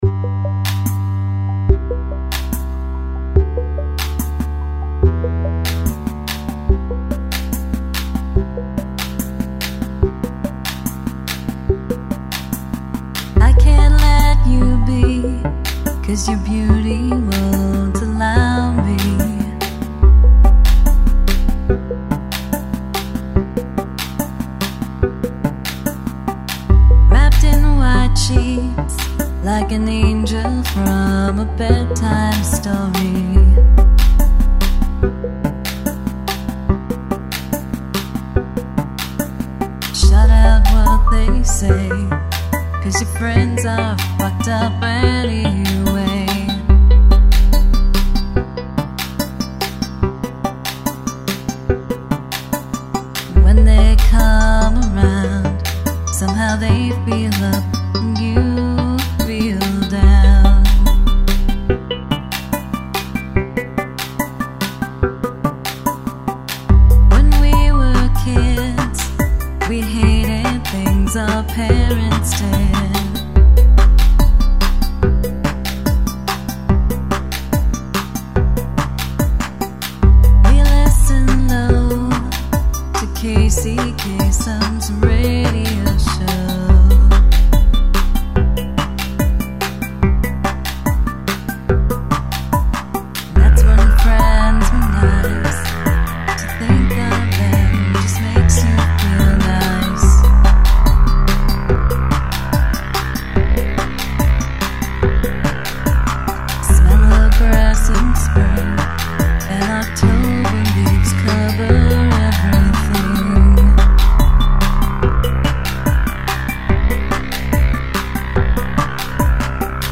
Jan '04 Remix: